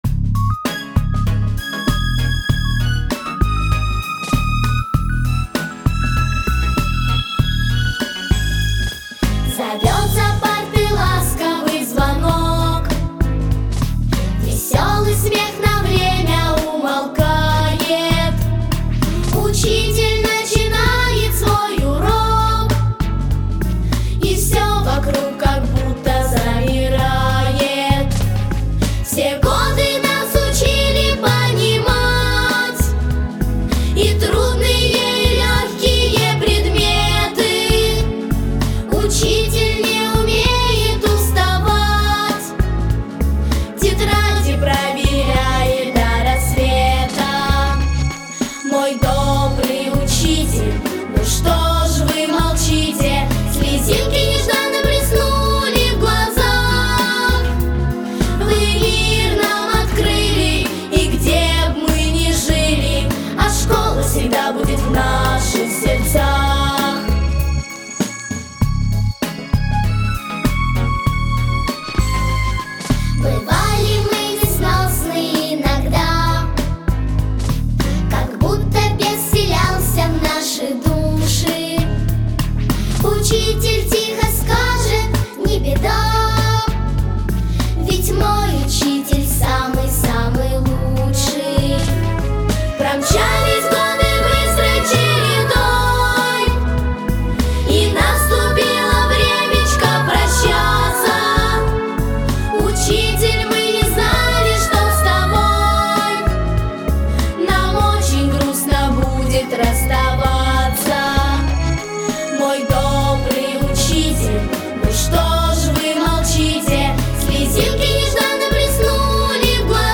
Главная » Файлы » детские песни